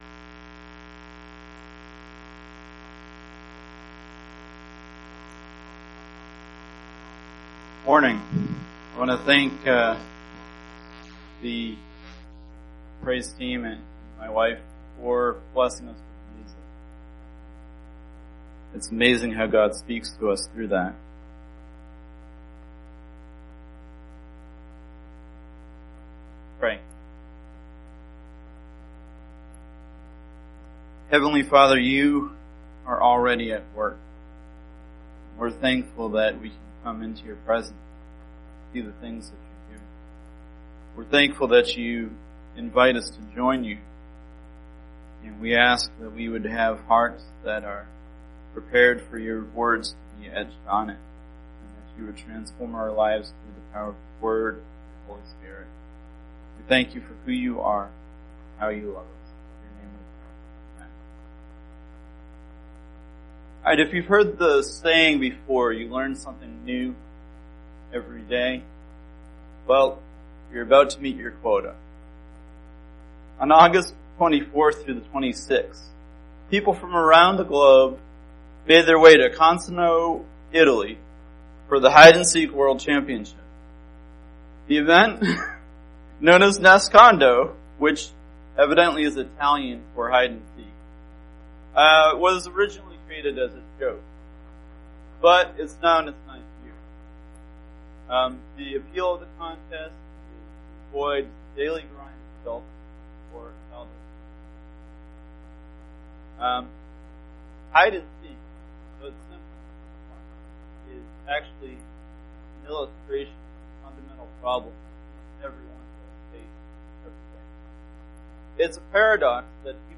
September 2, 2018 Sermons, Speaker